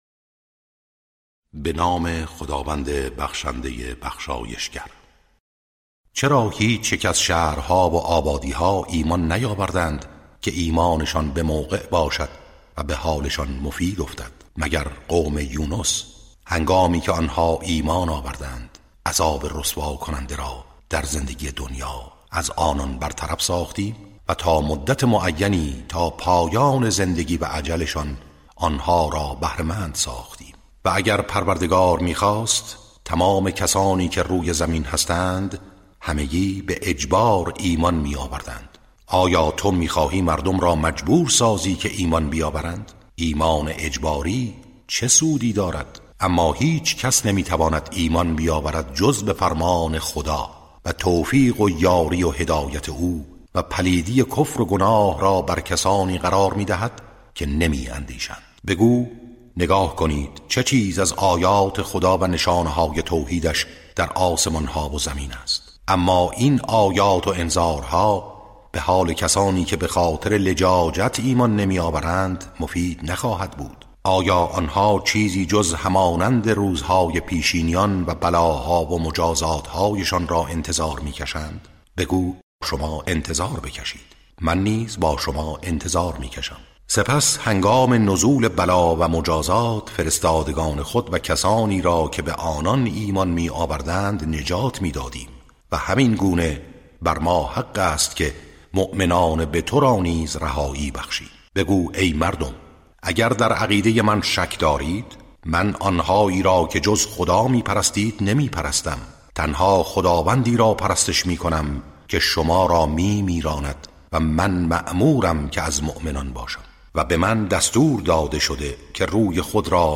ترتیل صفحه ۲۲۰ سوره مبارکه یونس(جزء یازدهم)
ترتیل سوره(یونس)